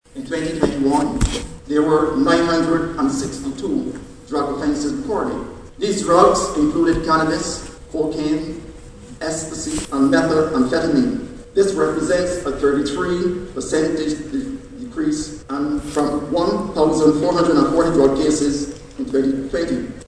Speaking this morning during the Barbados Police Service’s Annual Grand conference, Mr Boyce said that focus has been placed on reducing the number of firearms on the streets which he notes is still a concern.